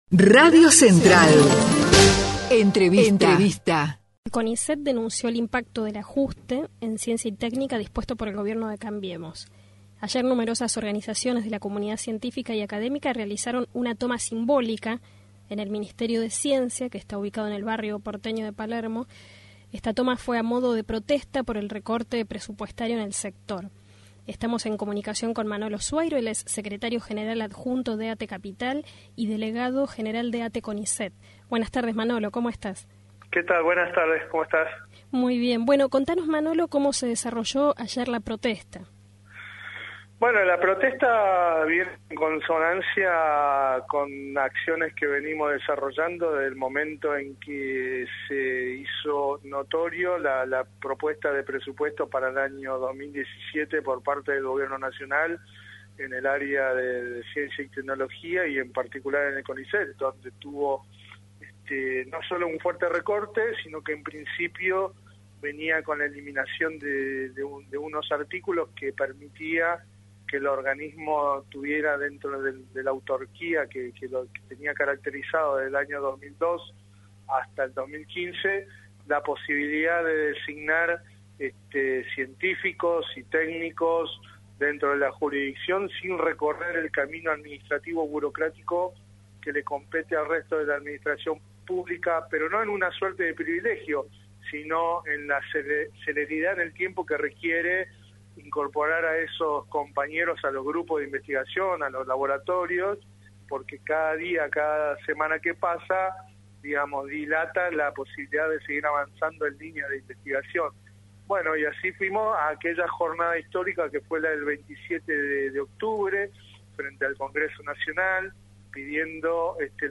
En diálogo con Radio Central